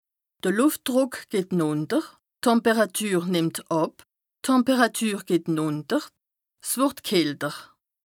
Bas Rhin
Ville Prononciation 67
2APRESTA_OLCA_LEXIQUE_METEO_AIR_BAS_RHIN_122_0.mp3